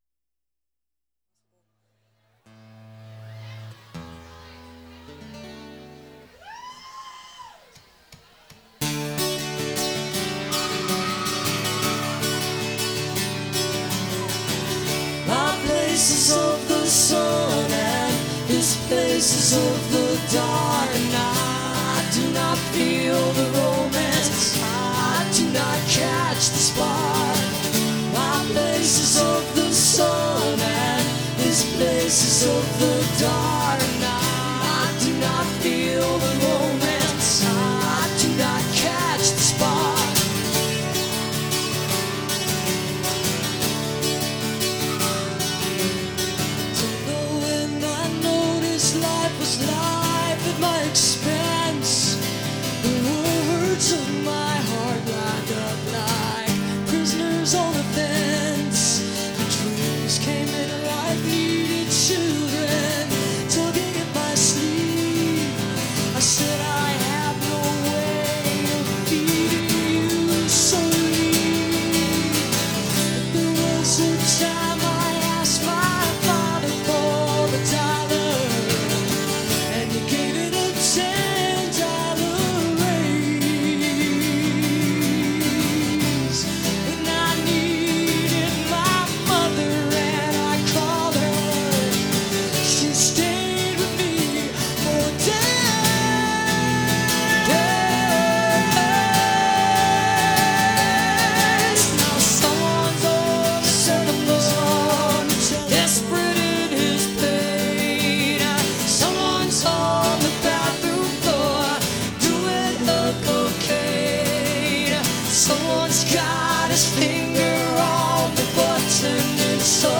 1989-08-31: the uptown lounge - athens, georgia